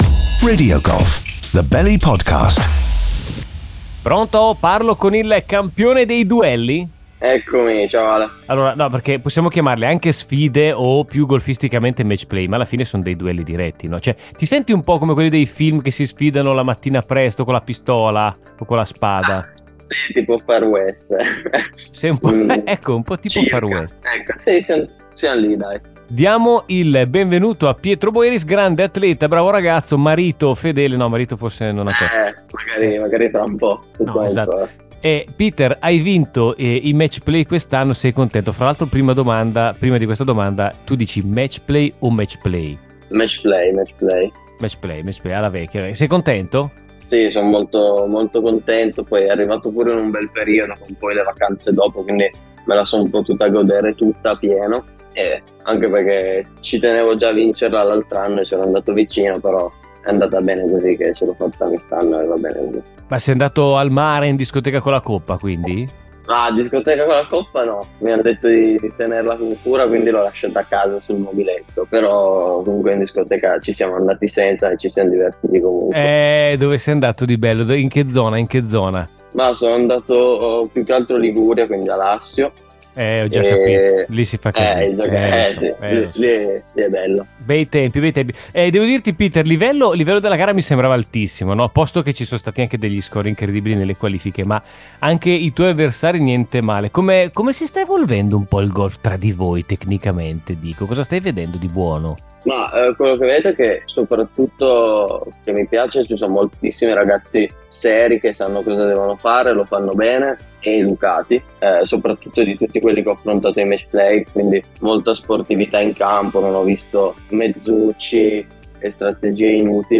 Nota: ci sono anche domande serie, di tanto in tanto.